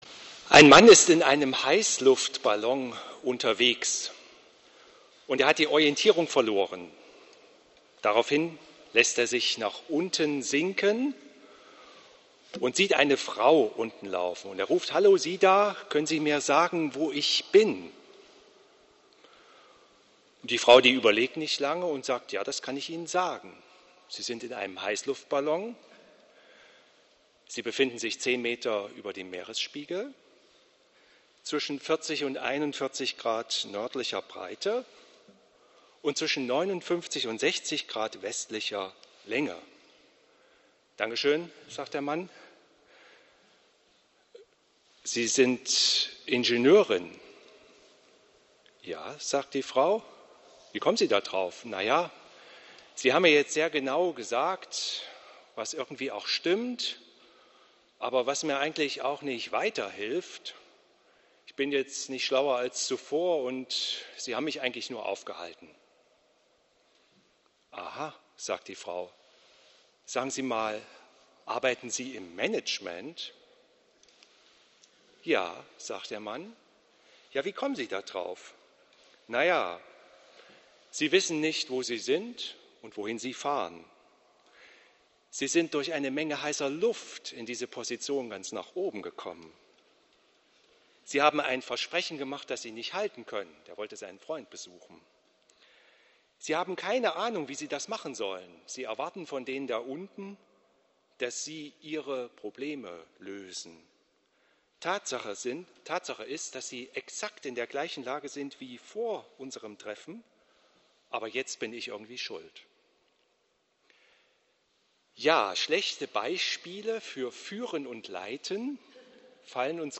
verbunden mit der Predigt zu Hesekiel 34